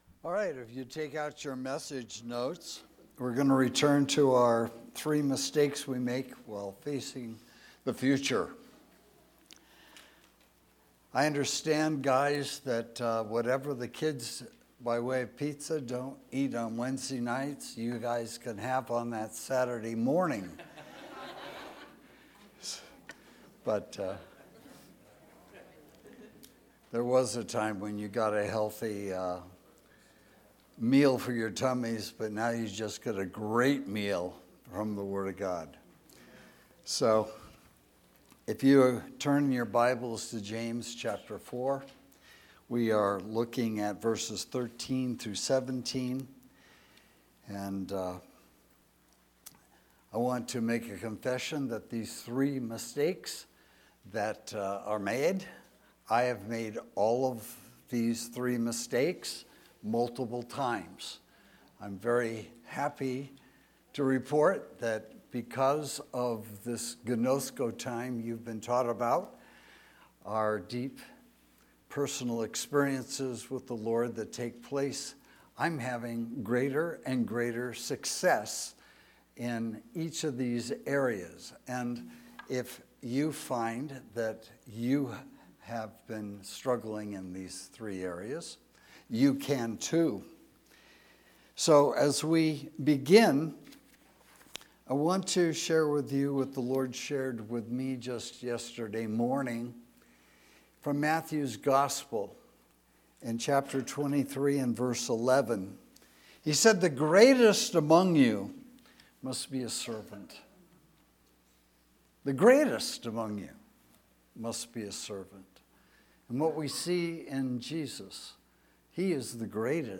Sermons – Page 36 – Granada Hills Community Church